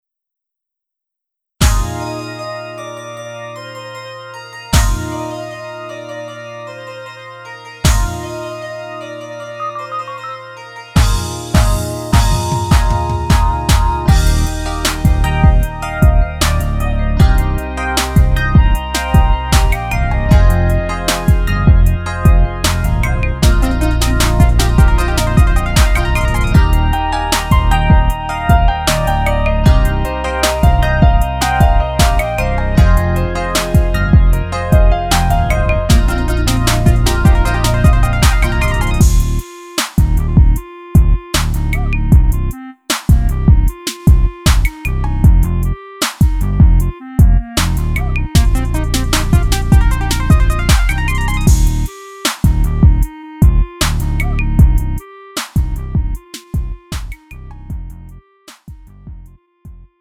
장르 구분 Lite MR